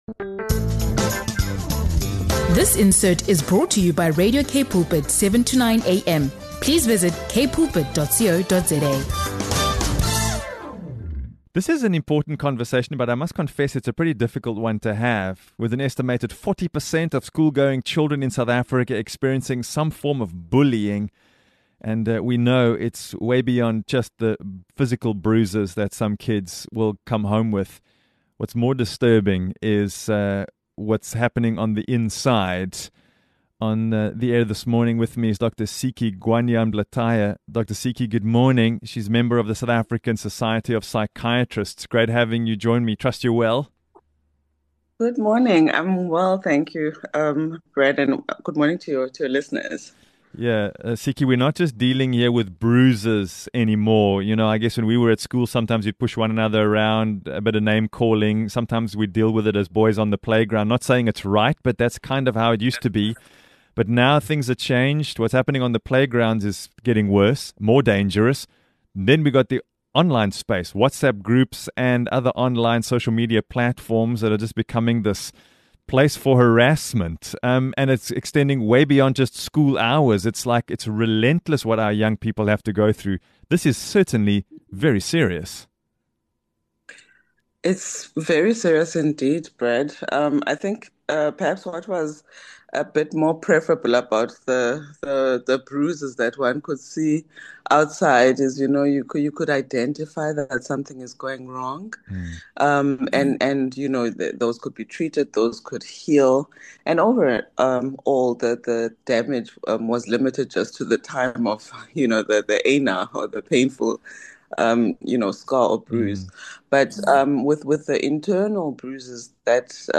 From playground cruelty to online harassment, she explains how bullying leaves lasting internal scars and why bullies themselves often need support. This powerful conversation urges parents, caregivers, and schools to communicate better, pay attention to behavioral changes, and implement effective bullying policies.